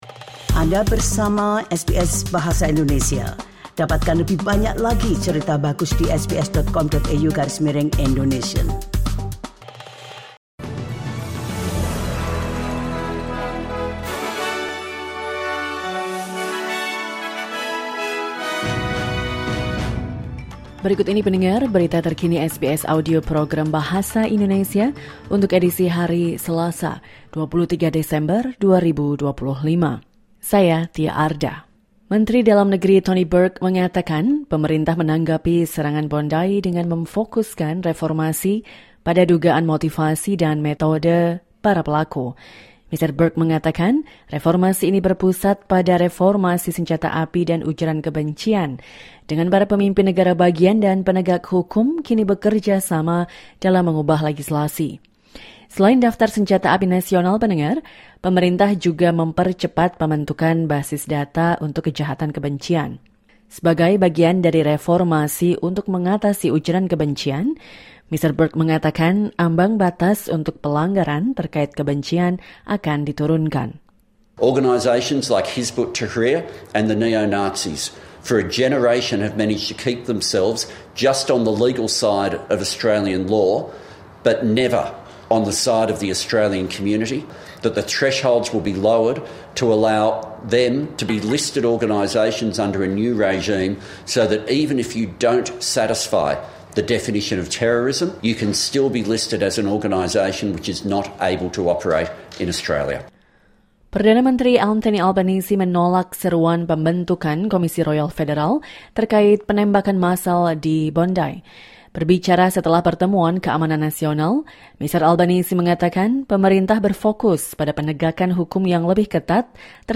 Berita Terkini SBS Audio Program Bahasa Indonesia - Selasa 23 Desember 2025